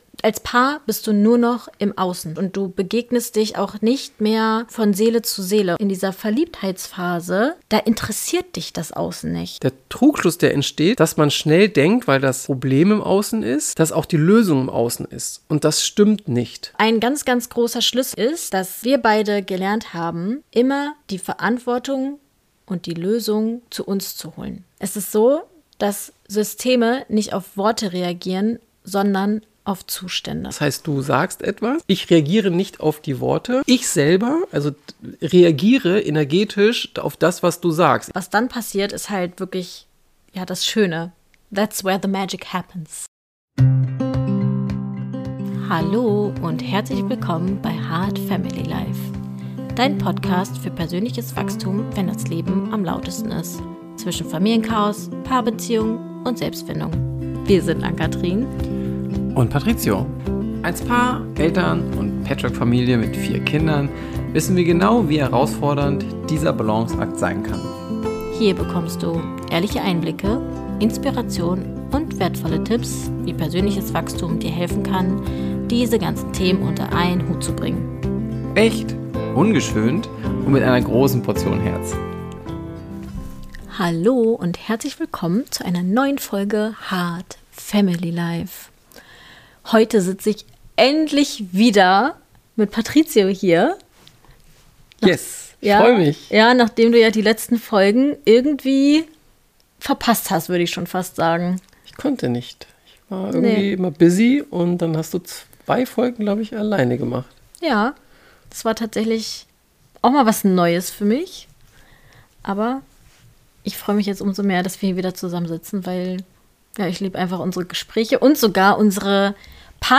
Ruhig.